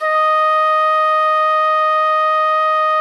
FluteClean2_D#3.wav